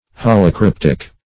holocryptic - definition of holocryptic - synonyms, pronunciation, spelling from Free Dictionary
Search Result for " holocryptic" : The Collaborative International Dictionary of English v.0.48: Holocryptic \Hol`o*cryp"tic\, a. [Holo- + Gr. kry`ptein to conceal.]